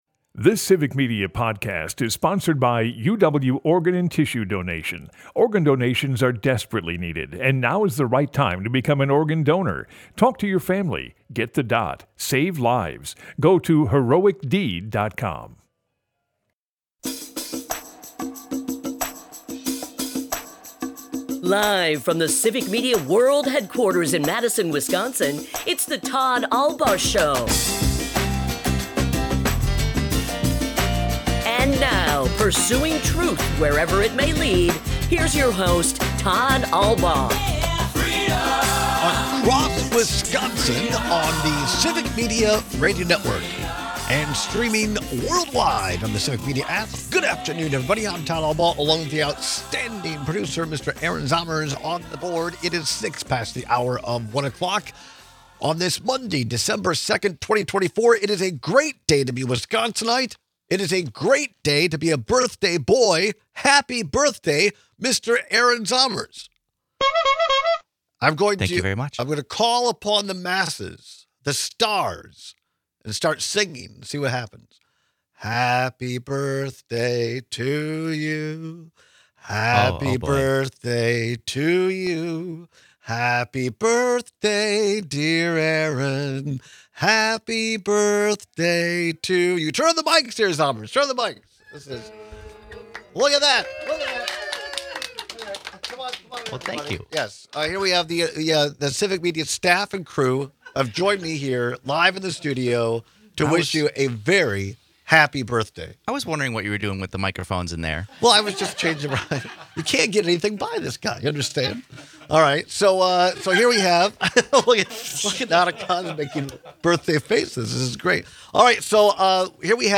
Broadcasts live 12 - 2p across Wisconsin.
Is the team worse under Luke Fickell or Don Morton? We take calls and texts with your thoughts.